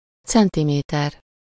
Ääntäminen
Ääntäminen France: IPA: [sɑ̃.t͡si.mɛtʁ] Tuntematon aksentti: IPA: /sɑ̃.ti.mɛtʁ/ Haettu sana löytyi näillä lähdekielillä: ranska Käännös Ääninäyte 1. centiméter Suku: m .